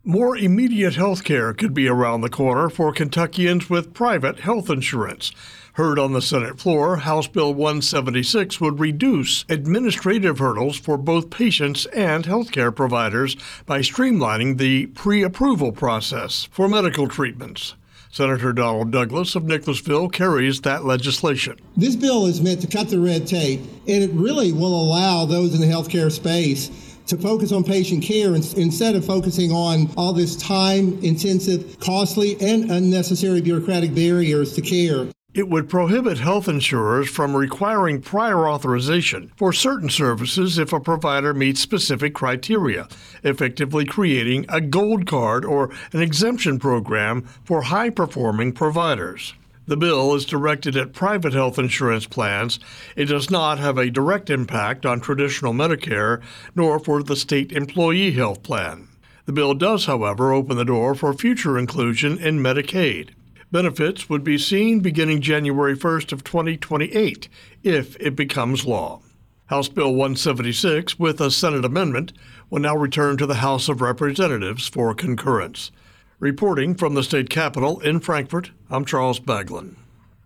LRC Public Information offers audio feeds over the Internet to help radio news directors obtain timely actualities and pre-produced news spots.